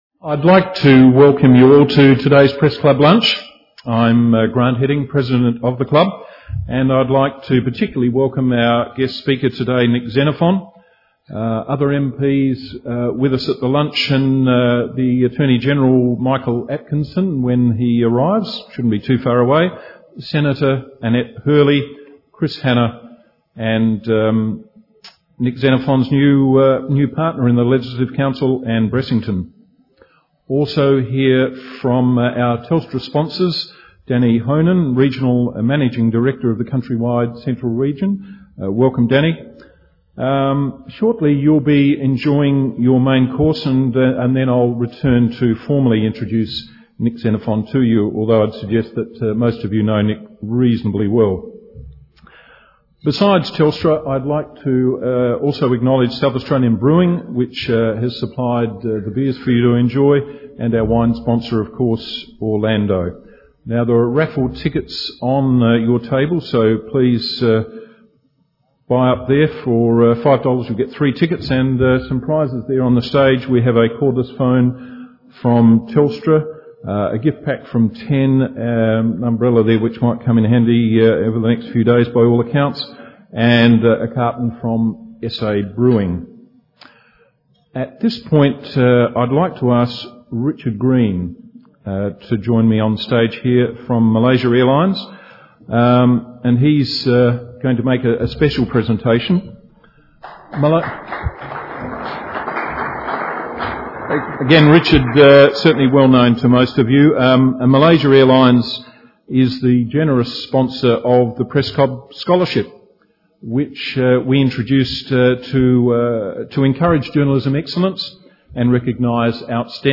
Nick Xenophon MLC May 5, 2006 @ 10:07 am No Pokies 5 May 2006 Download .mp3 (8.4MB) Details Date: May 5, 2006 Time: 10:07 am Event Category: Luncheons Add to calendar Google Calendar iCalendar Outlook 365 Outlook Live